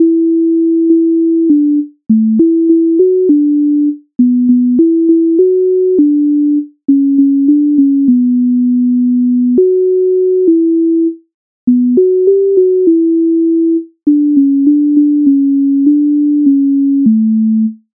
MIDI файл завантажено в тональності D-dur